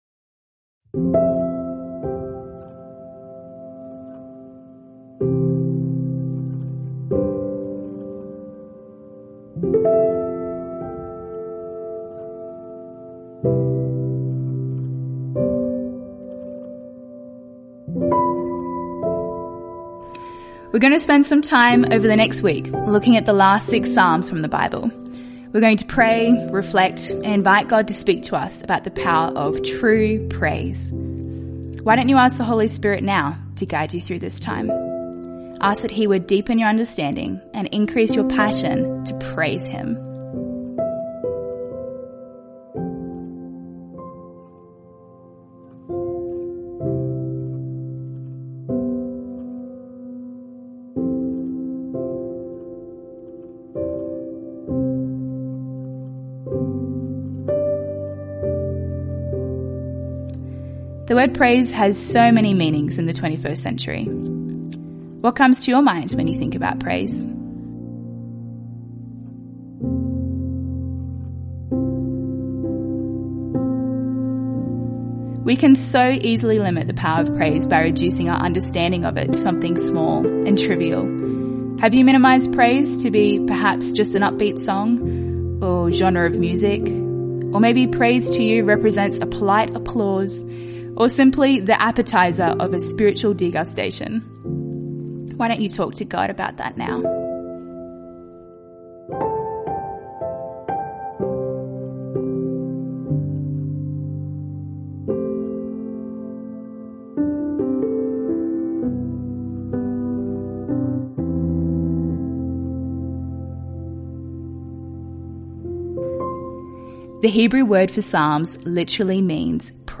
Use the audio guide provided to practice praise as we share together in joy, in song, and the written word.